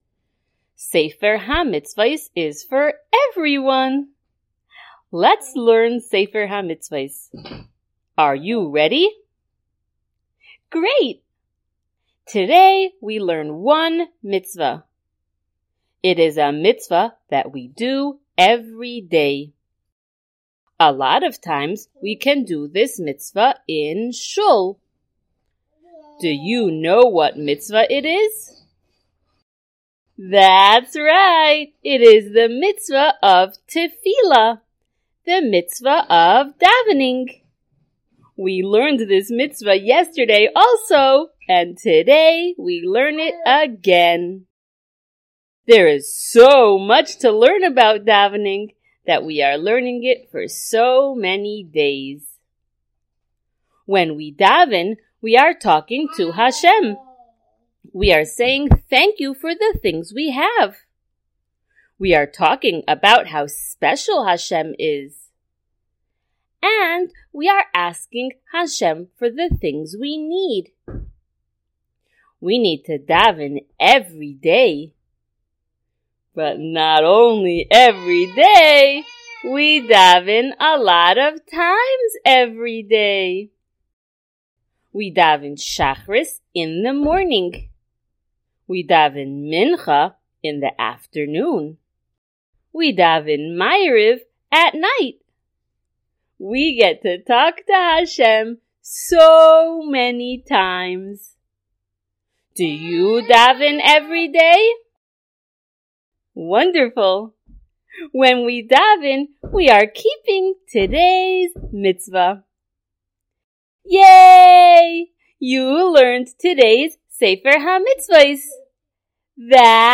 SmallChildren_Shiur025.mp3